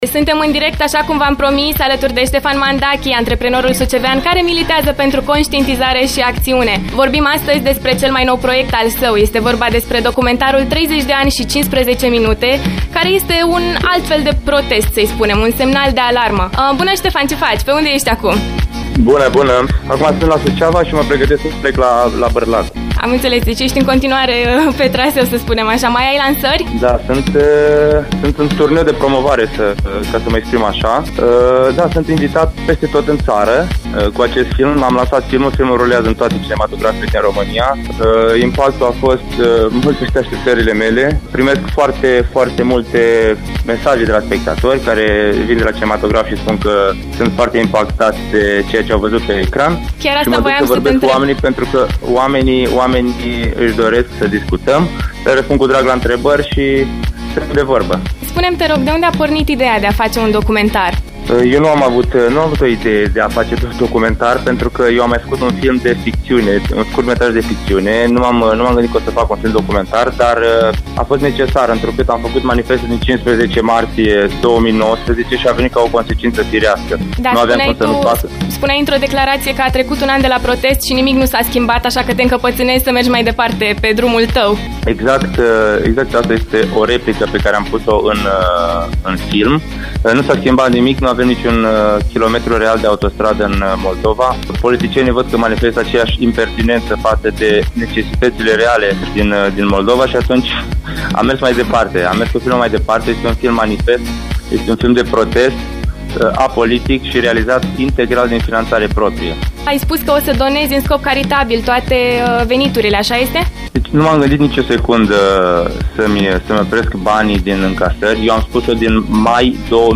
Home Emisiuni After Morning Ediție specială la AFTER MORNING!